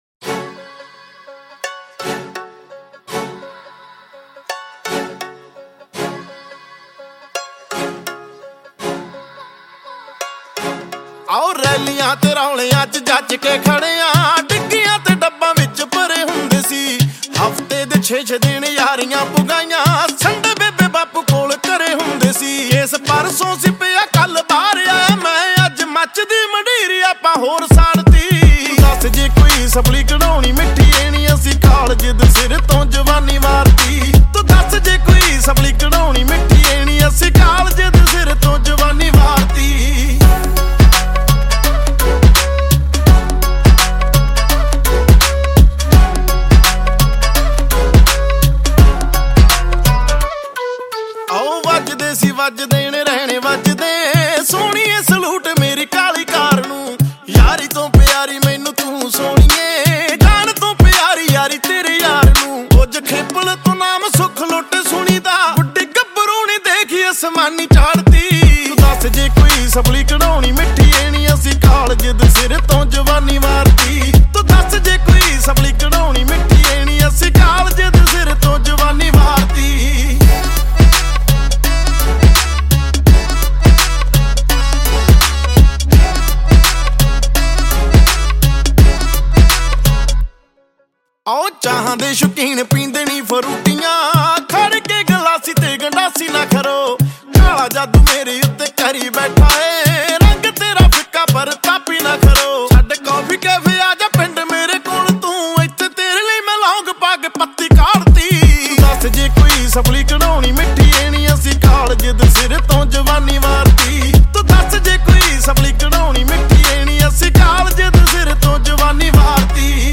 2. Punjabi Single Track